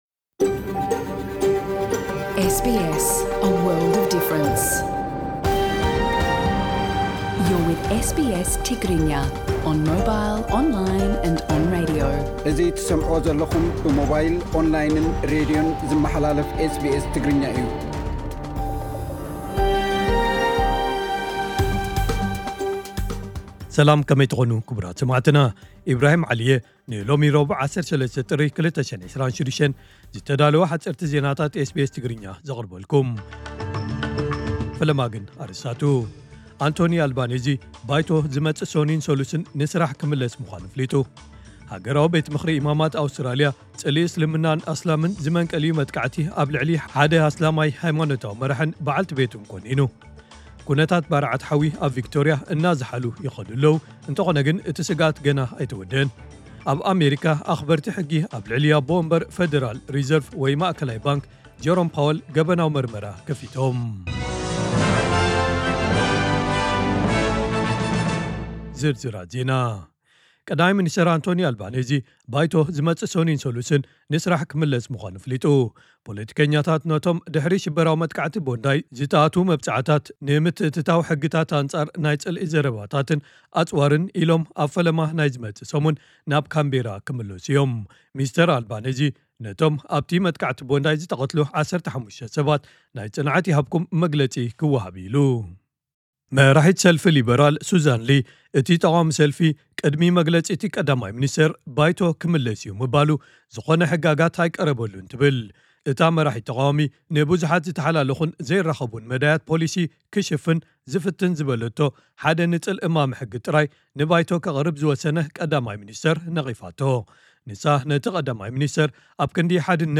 ዕለታዊ ዜና ኤስቢኤስ ትግርኛ (13 ጥሪ 2026)